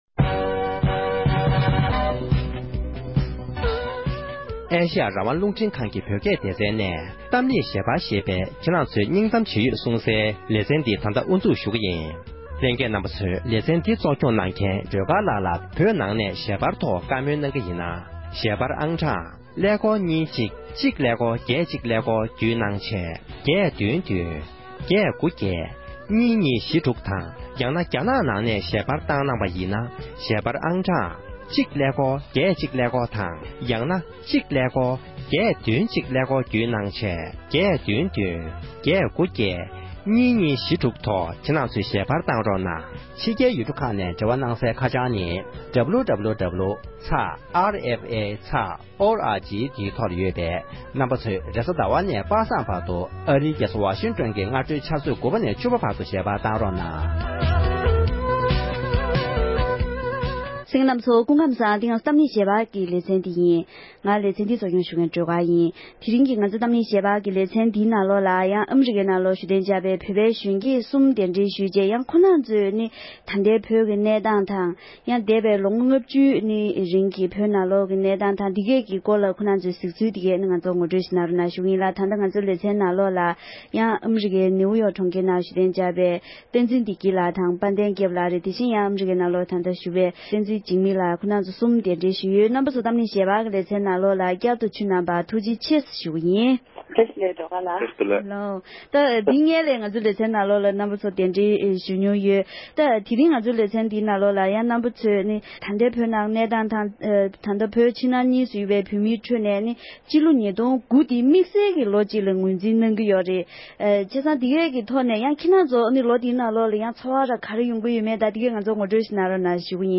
༄༅༎དེ་རིང་གི་གཏམ་གླེང་ཞལ་པར་གྱི་ལེ་ཚན་ནང་དུ་བཙན་བྱོལ་ནང་འཚར་ལོངས་བྱུང་བའི་བོད་པའི་གཞོན་སྐྱེས་ཁག་ཅིག་དང་ལྷན་དུ་བོད་ཀྱི་གནད་དོན་ཐོག་